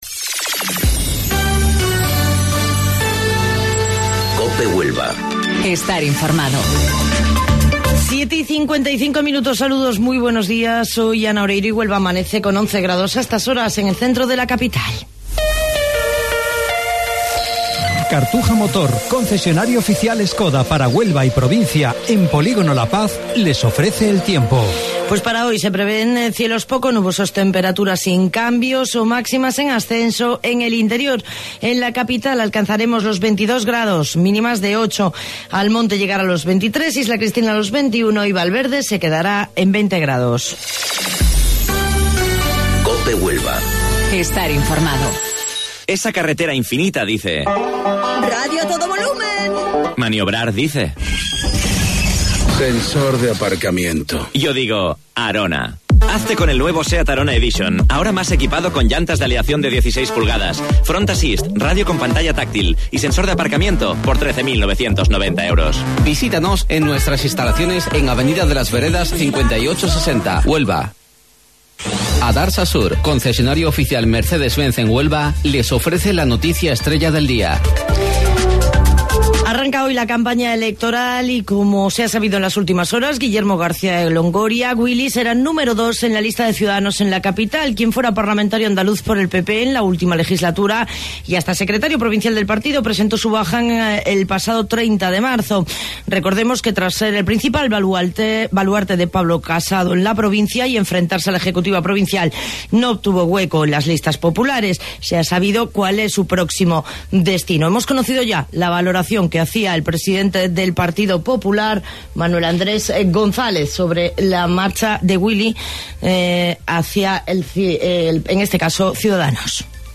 AUDIO: Informativo Local 07:55 del 11 de Abril